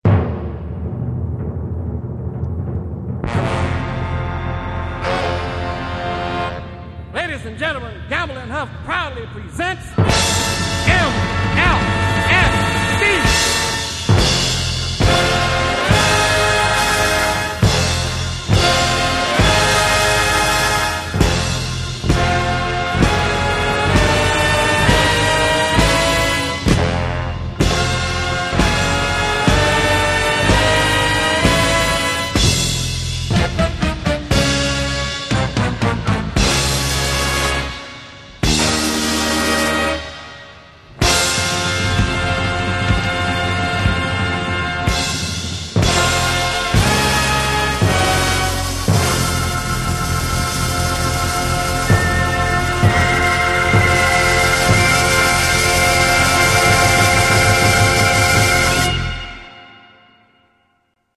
Genere:   Disco | Soul